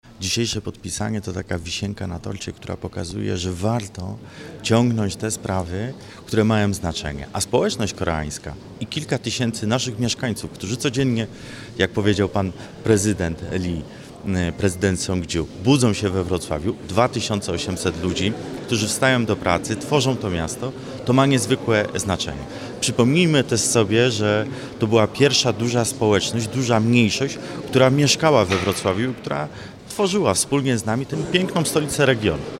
W Sali Wielkiej Starego Ratusza doszło do podpisania umowy partnerskiej między stolicą Dolnego Śląska a południowokoreańskim Cheongju.
Dzięki rozmowom z południowokoreańskimi partnerami na długo przed podpisaniem umowy stało się również możliwe przeprowadzenie projektów o międzynarodowym i międzygminnym charakterze. Mówi Jakub Mazur, Wiceprezydent Wrocławia.